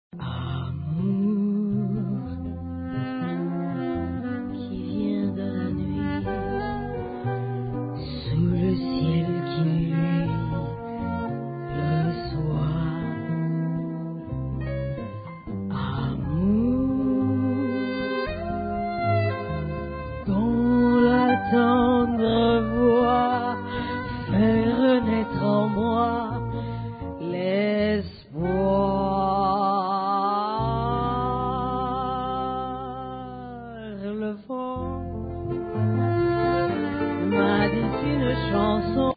Violin
Sax, vocals
Grand Piano
Guitars
Live recording Nieuwe de la Mar theater Amsterdam